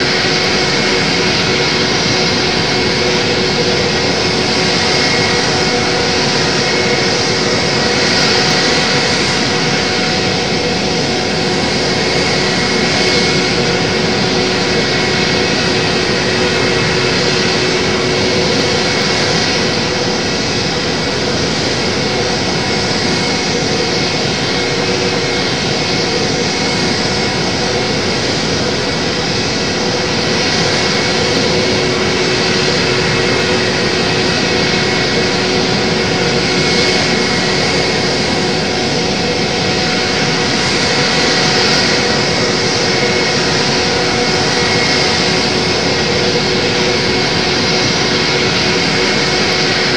Add external IAE sounds
v2500-idle.wav